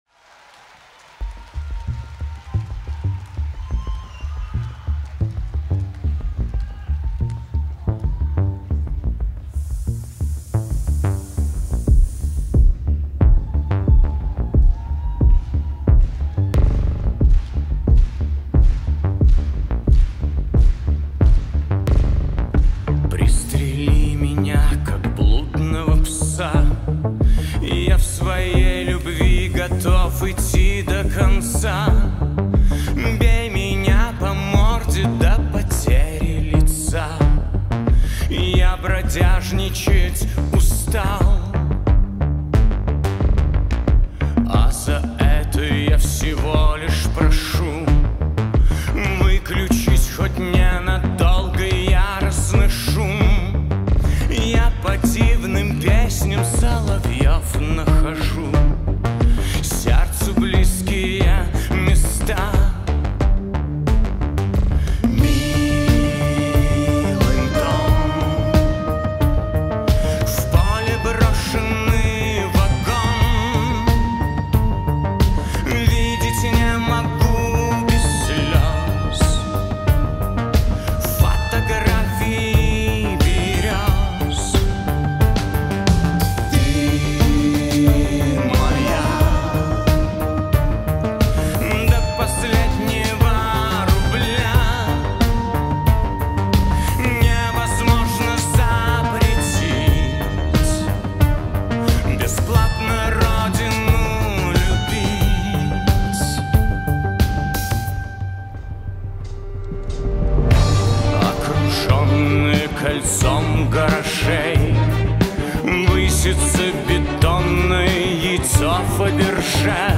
Альбом: Rock